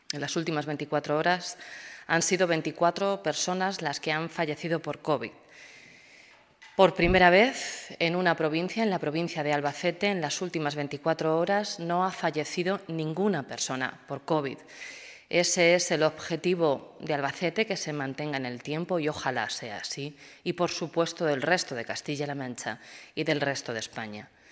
Blanca Fernández, portavoz del Gobierno de Castilla-La Mancha.
A esta situación se ha referido la consejera de Igualdad y portavoz del Gobierno, Blanca Fernández, durante su comparencia de hoy.